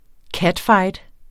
Udtale [ ˈkadˌfɑjd ]